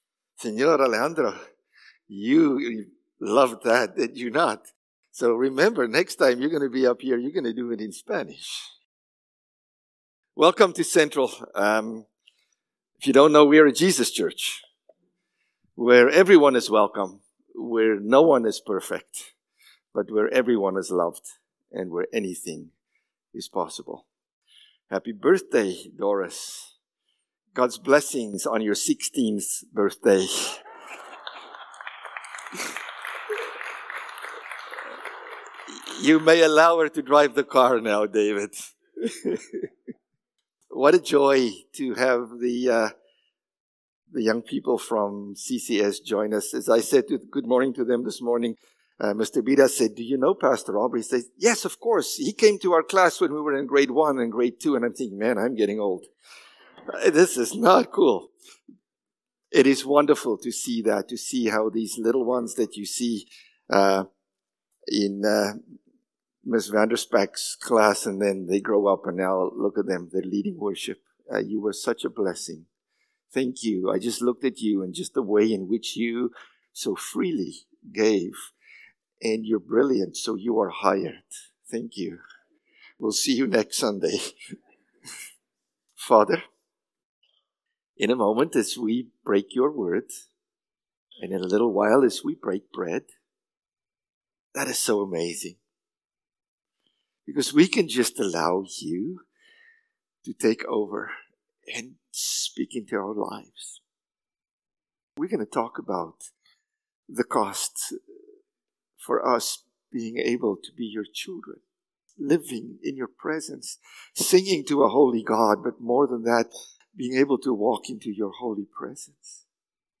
November-17-Sermon.mp3